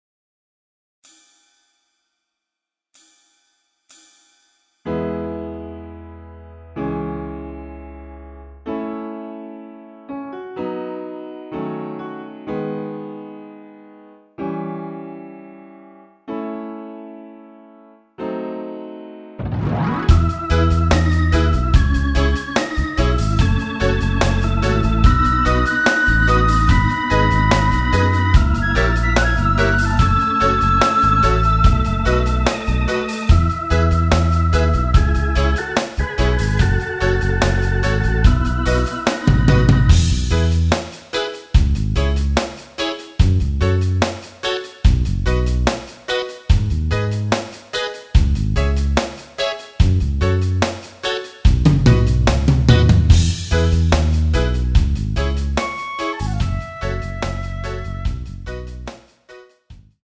전주 없이 시작 하는곡이라 카운트 넣었으며 엔딩이 페이드 아웃이라 엔딩도 만들어 놓았습니다.(미리듣기 참조)
앞부분30초, 뒷부분30초씩 편집해서 올려 드리고 있습니다.
중간에 음이 끈어지고 다시 나오는 이유는